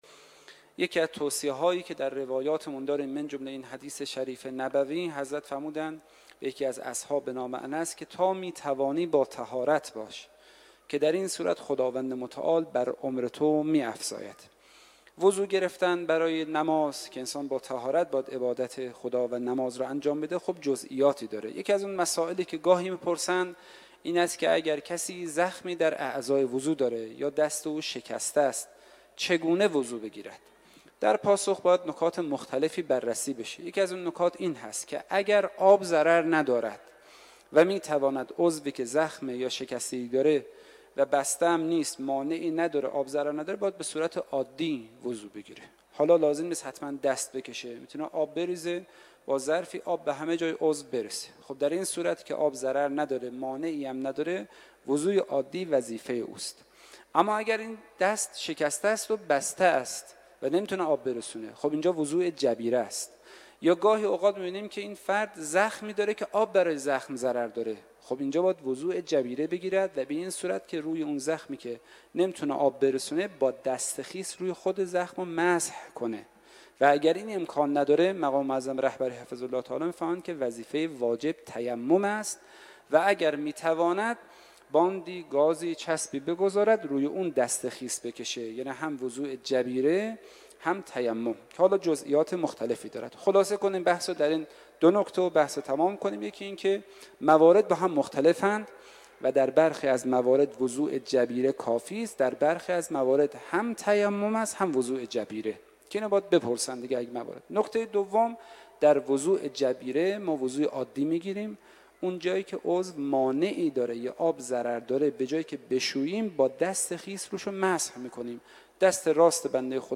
کارشناس پاسخگویی